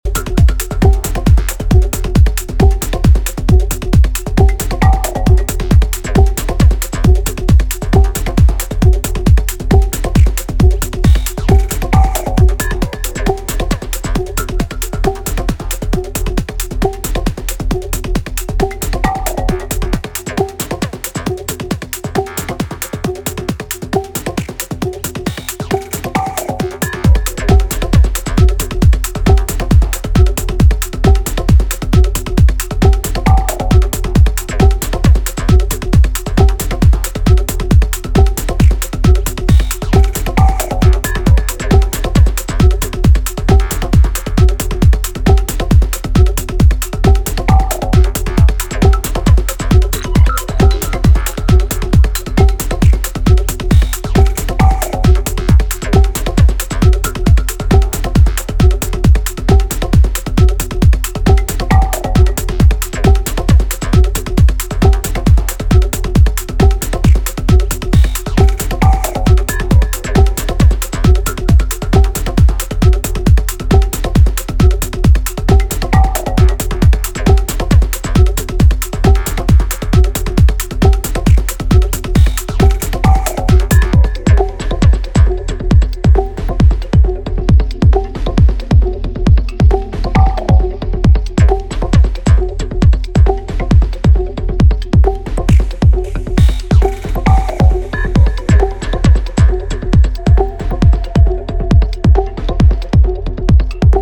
Electronix Ambient Experimental